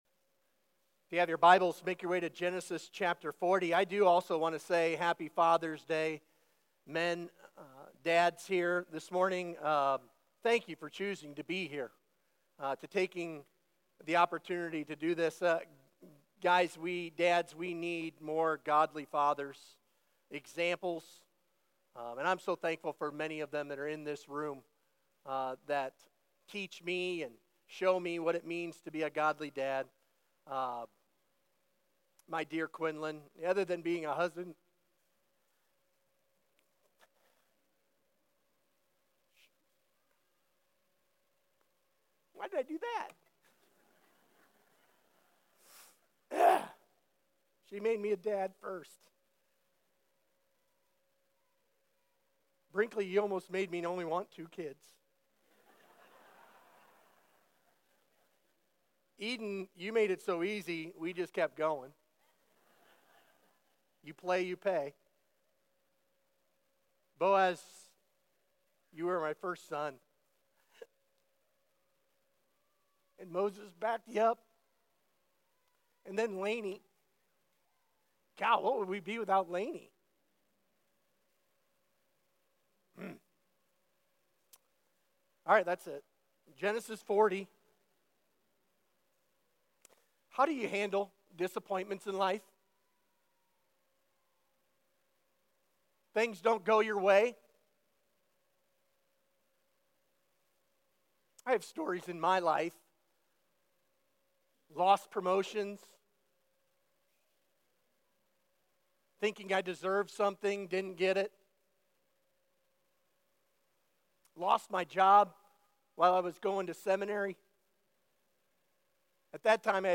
Sermon Questions God at Work in Disappointment Read Genesis 40:1-23.